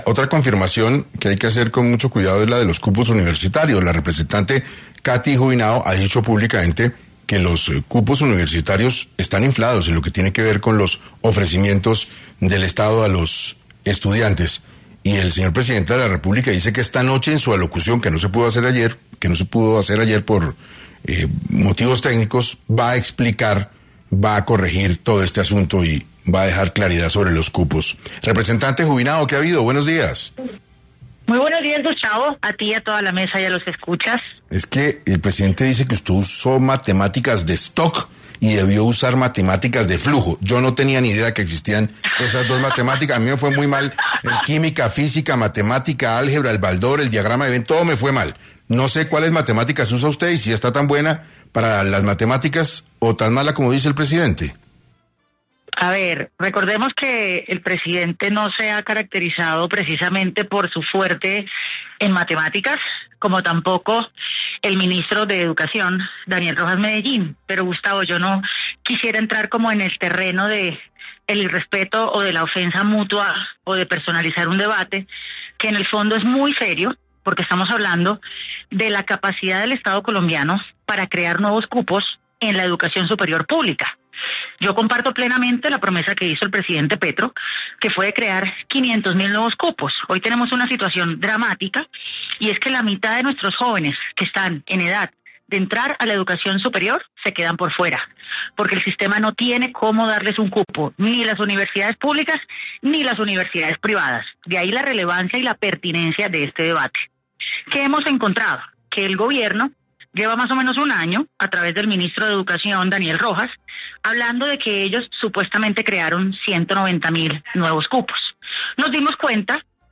Catherine Juvinao Clavijo, representante a la Cámara, estuvo en 6AM para abordar temas relacionados con la educación superior e información que induce al error suministrada por el Gobierno.